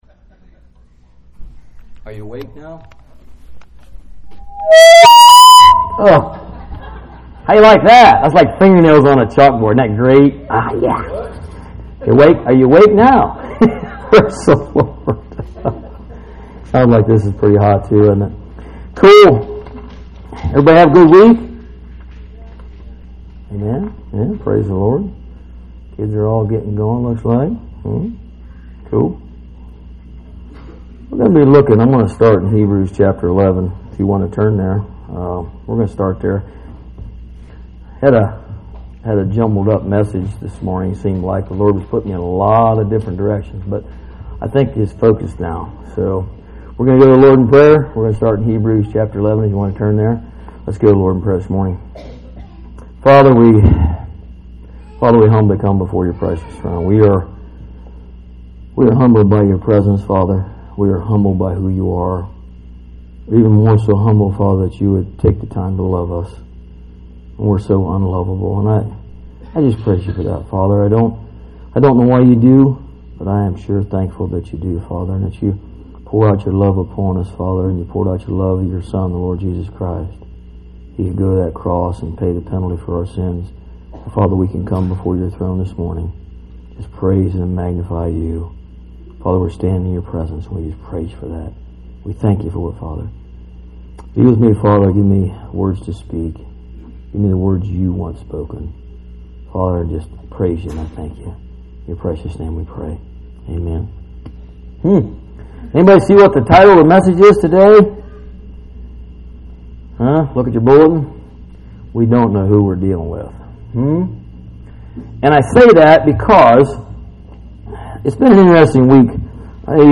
Bible Text: Hebrews 11:1-2, Hebrews 11:32-40, Romans 15:8-13, Revelation 7:9-17, Psalm 46:1-11, Hebrews 10:28-31 | Preacher